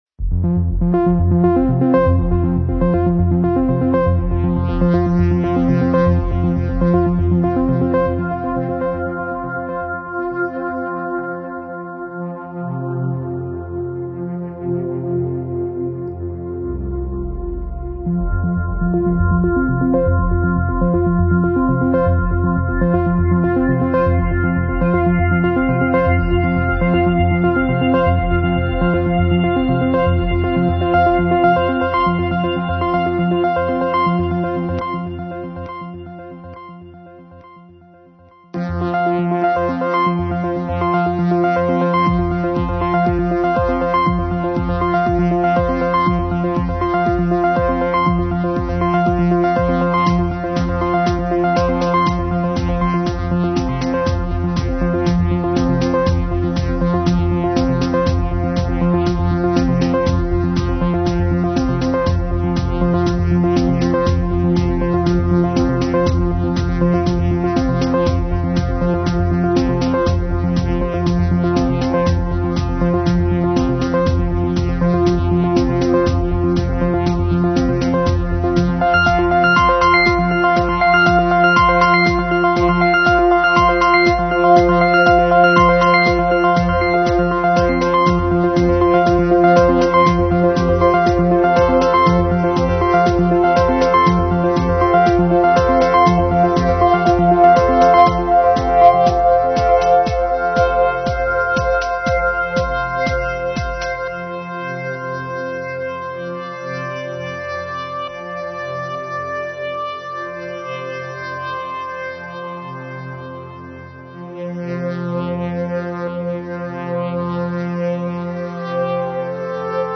dance/electronic
Techno
Ambient